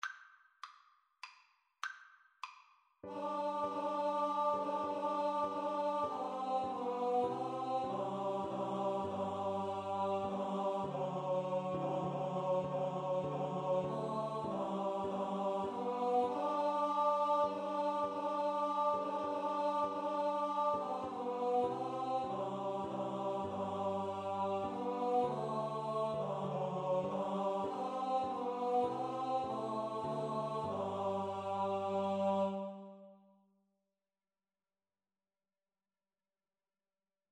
• Choir (SATB)
3/4 (View more 3/4 Music)